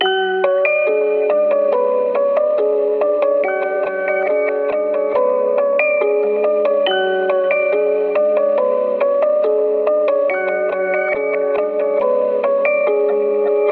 child's play [140 bpm].wav